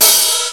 Index of /m8-backup/M8/Samples/Fairlight CMI/IIX/CYMBALS